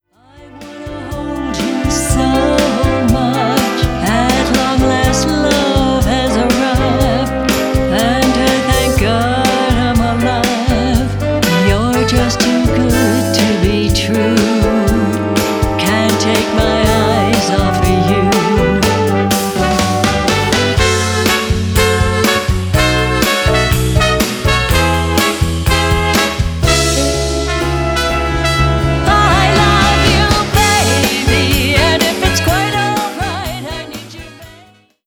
Lead Vocal
Guitars
Keys / Organ / Bass
Drums / Tambourine
Trombone
Trumpet
Saxophone